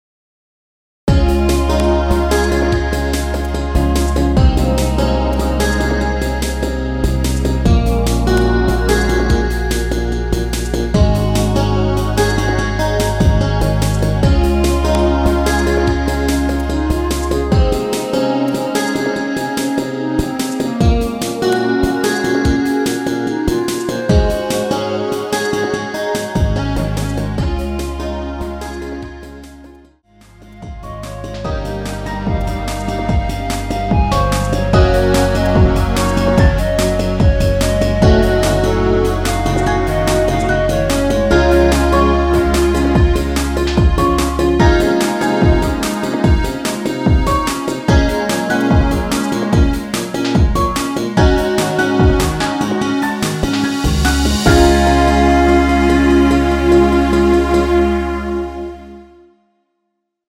엔딩이 길고 페이드 아웃이라서 노래 끝나고 8마디 진행후 엔딩을 만들었습니다.
원키 멜로디 포함된 MR입니다.
Db
앞부분30초, 뒷부분30초씩 편집해서 올려 드리고 있습니다.